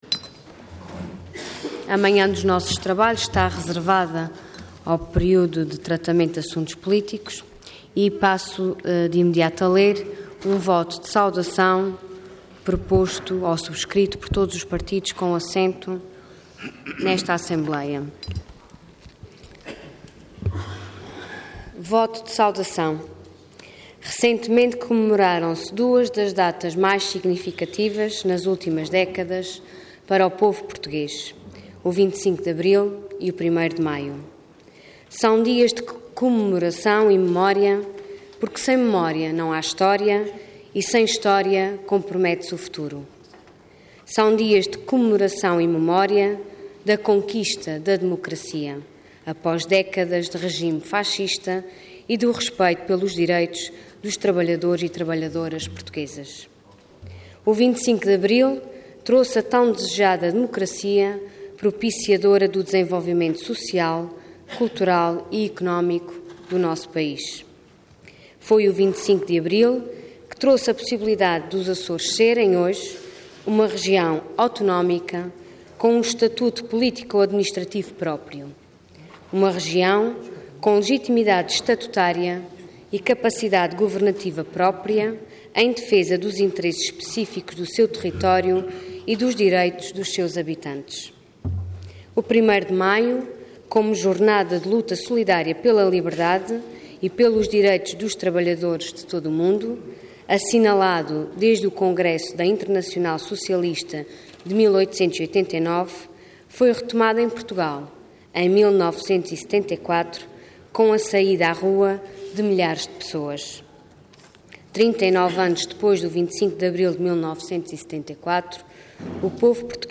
Intervenção Voto de Saudação Orador Ana Luísa Luís Cargo Deputado Entidade Voto Conjunto